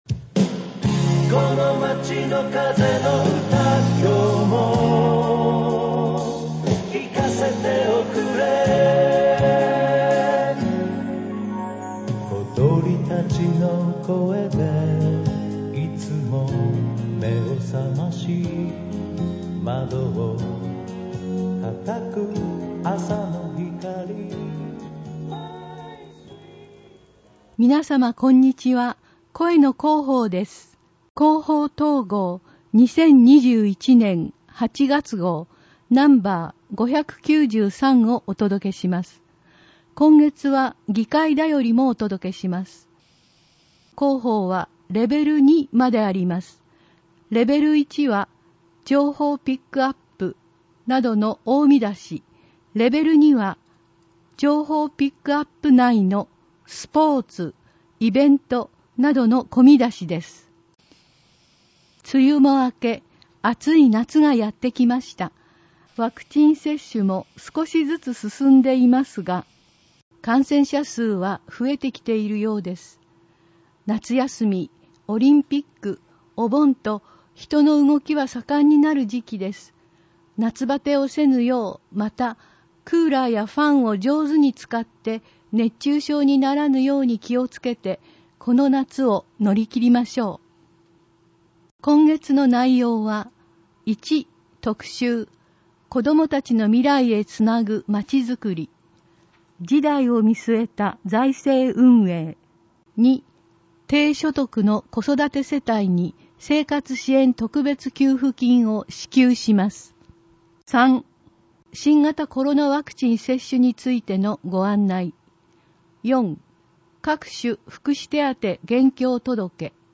広報とうごう音訳版（2021年8月号）